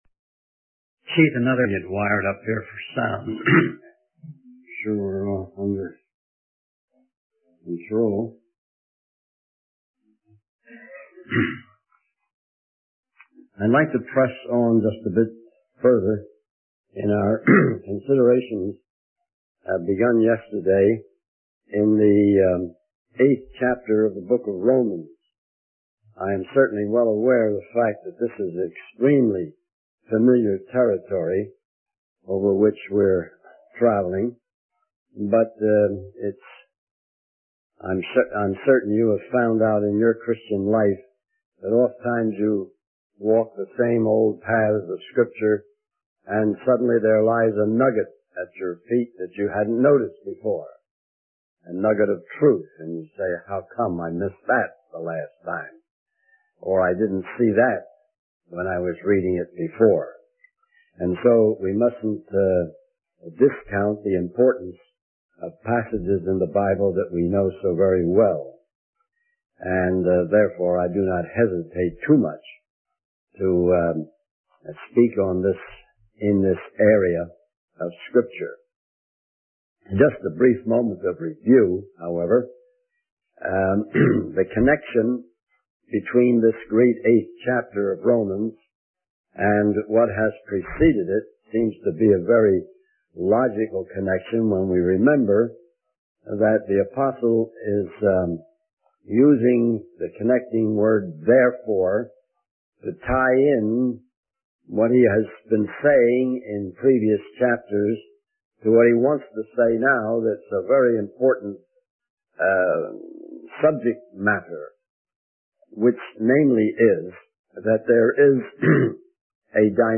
In this sermon, the speaker discusses the issue of watching movies and videos in the privacy of one's home.